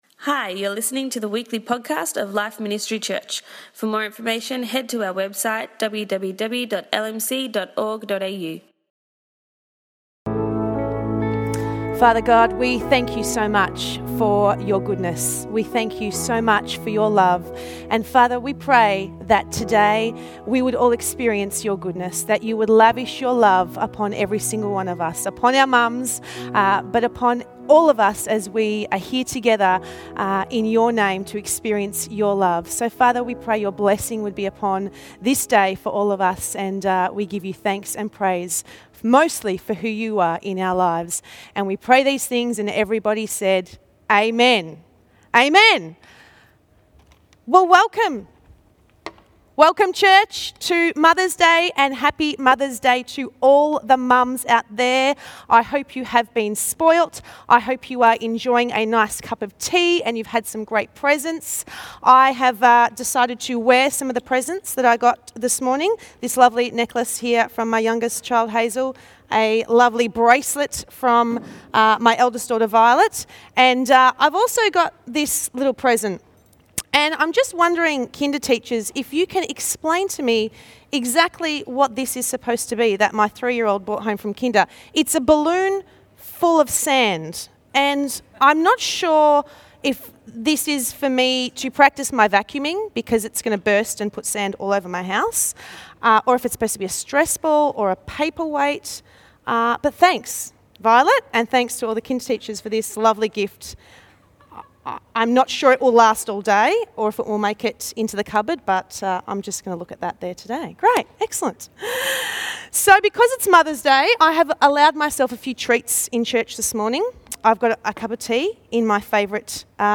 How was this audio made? In this year's Mother's Day message, we are reminded that although it might be easy to find something to complain about, we can always choose gratitude and thankfulness instead.